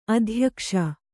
♪ adhyakṣa